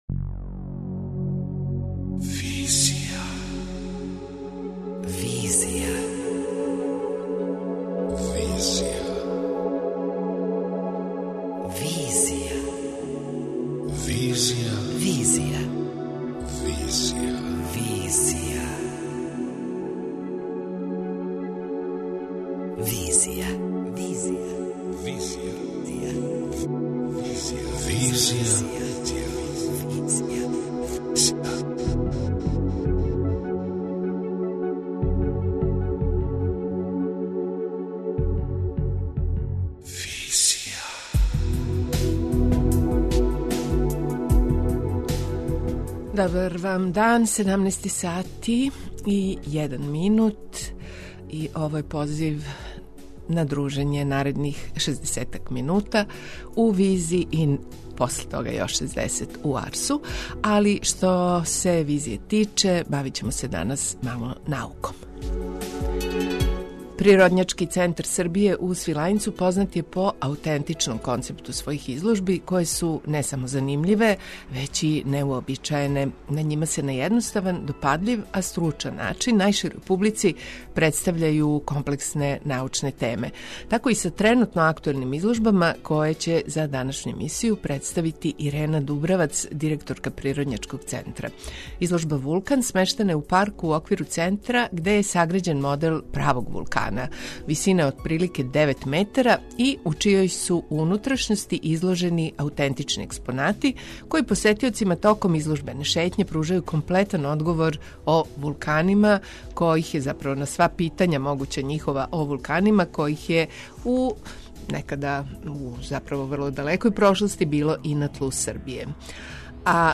преузми : 26.55 MB Визија Autor: Београд 202 Социо-културолошки магазин, који прати савремене друштвене феномене.